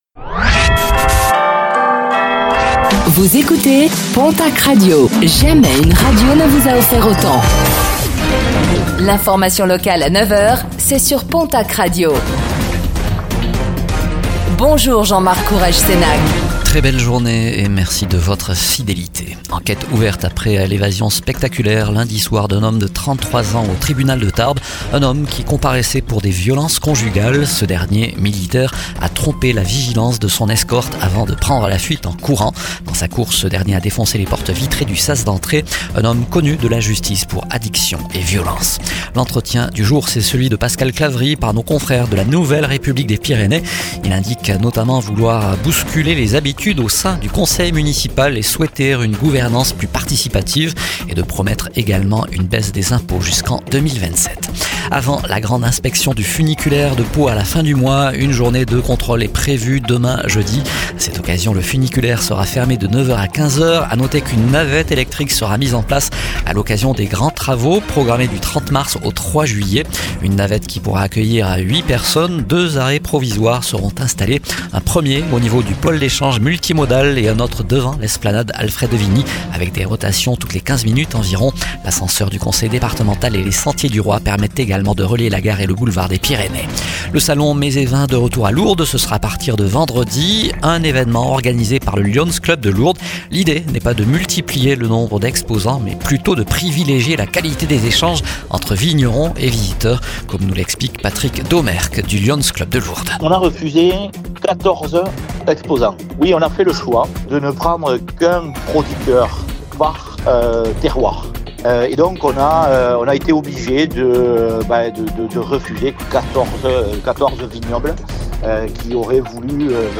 Réécoutez le flash d'information locale de ce mercredi 25 mars 2026